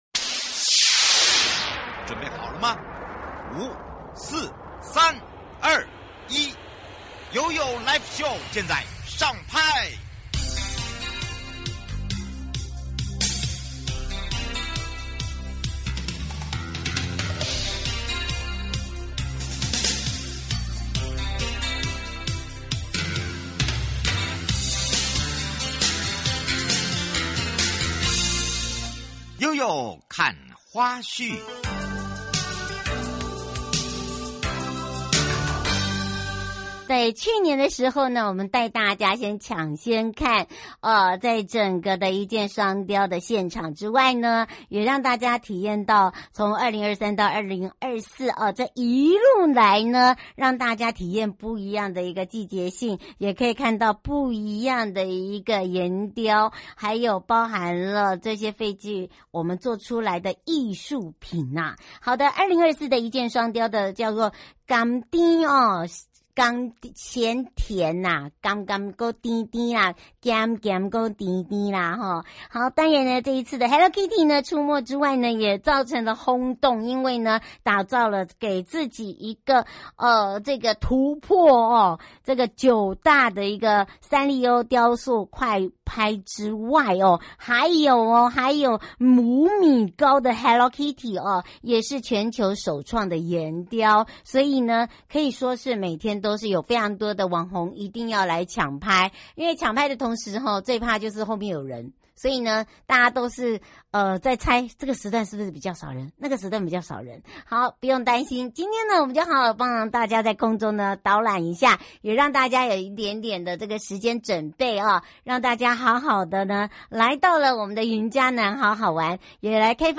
受訪者： 1. 雲嘉南管理處許宗民處長